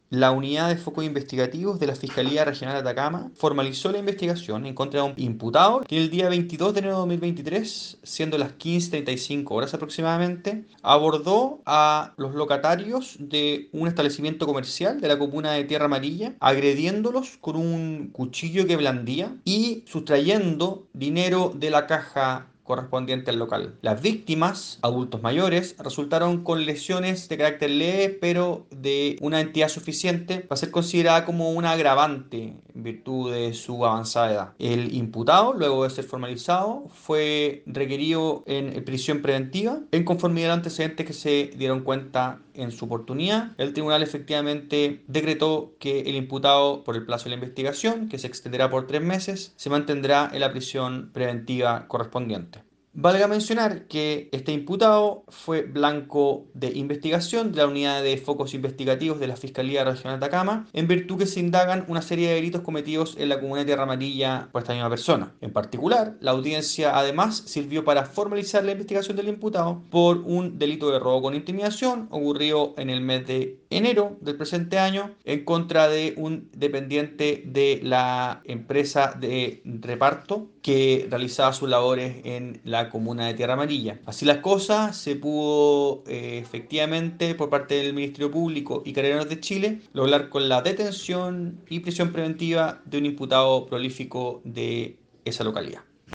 Audio Fiscal Renán Gallardo
AUDIO-FISCAL-RENAN-GALLARDO.mp3